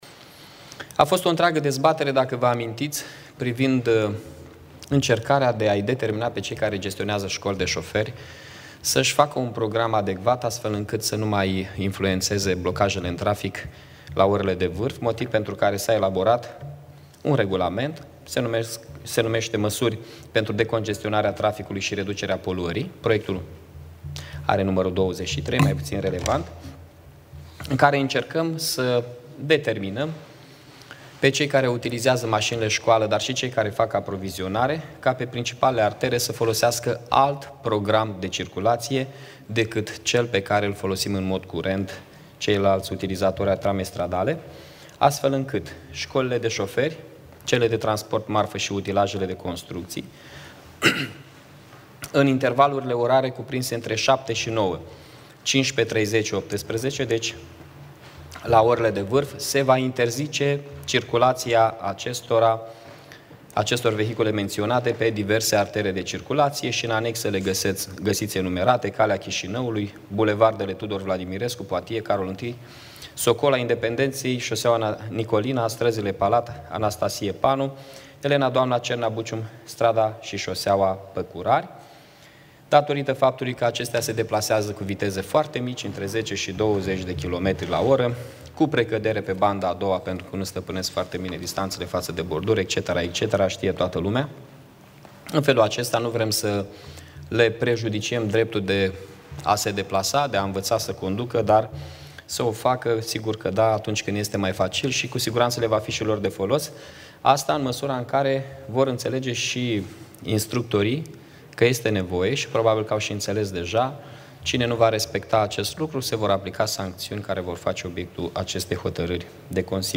Primarul Mihai Chirica
29-august-Mihai-Chirica-scoli-de-soferi.mp3